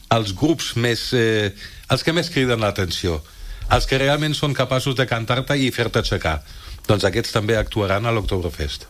en una entrevista al magazine A l’FM i + de Ràdio Calella TV